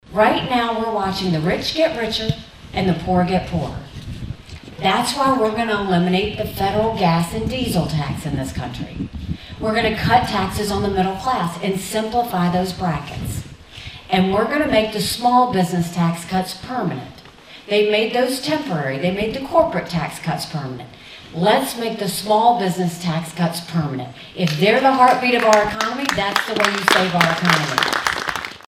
Republican Presidential Candidate Nikki Haley Makes Campaign Stop in Atlantic